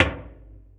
Index of /kb6/Akai_MPC500/1. Kits/Garage Kit
grg knock.WAV